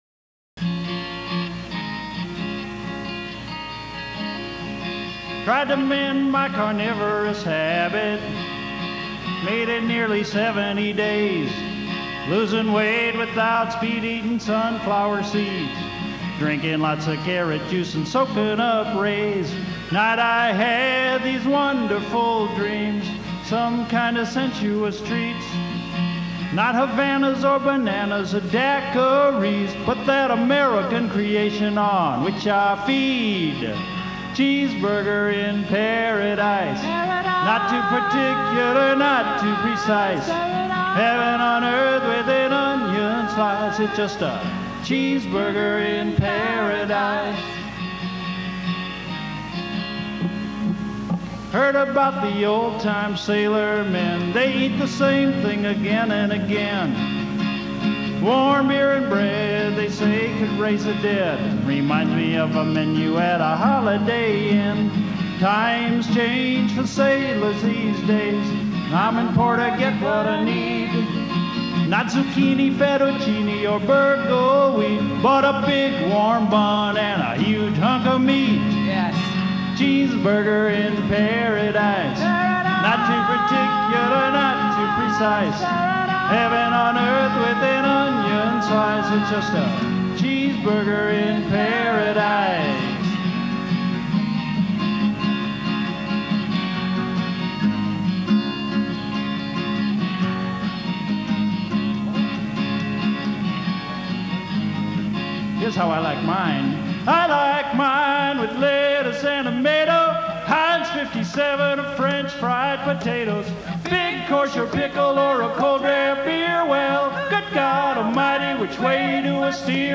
Category Rock/Pop
Studio/Live Live